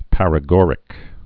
(părə-gôrĭk, -gŏr-)